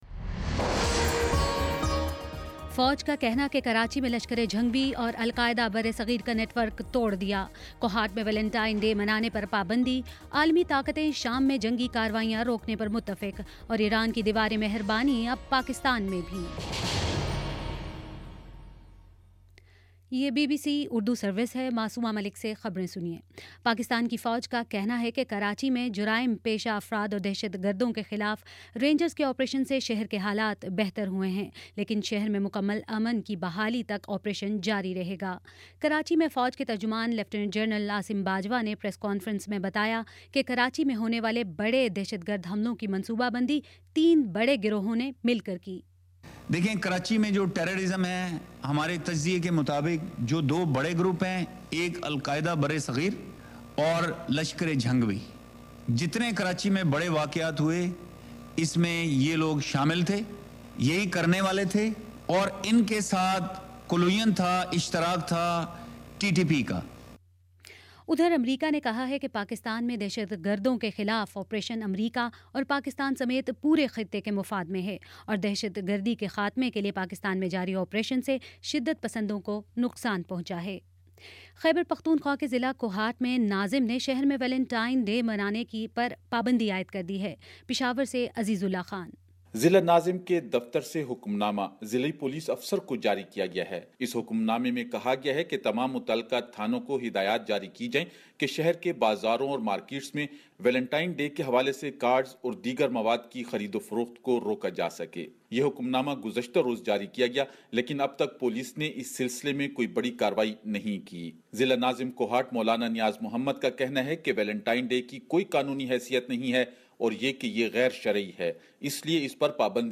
فروری 12 : شام پانچ بجے کا نیوز بُلیٹن